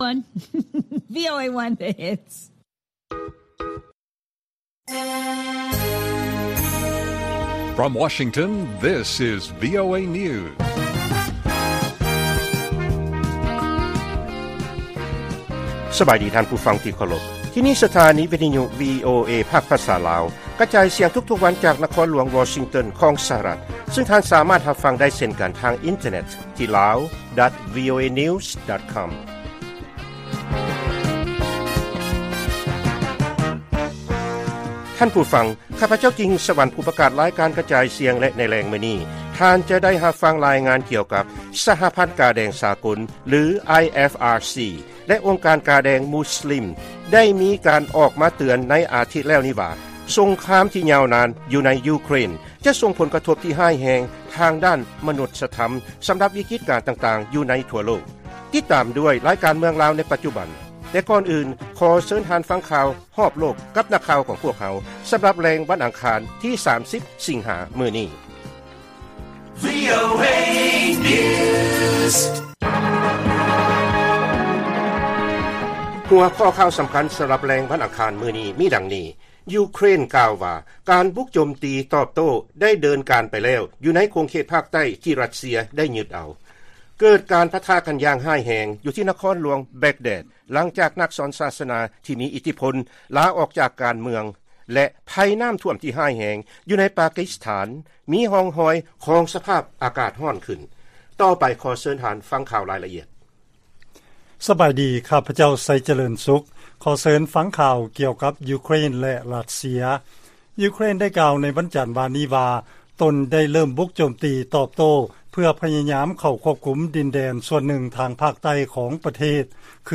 ລາຍການກະຈາຍສຽງຂອງວີໂອເອ ລາວ: ຢູເຄຣນ ກ່າວວ່າ ການບຸກໂຈມຕີຕອບໂຕ້ ໄດ້ດຳເນີນການໄປແລ້ວ ຢູ່ໃນຂົງເຂດພາກໃຕ້ ທີ່ຣັດເຊຍ ໄດ້ຢຶດເອົາ ໃນຕອນຕົ້ນຂອງສົງຄາມ